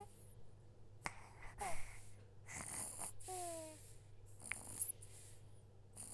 Baby Snoring Sound Effect Free Download
Baby Snoring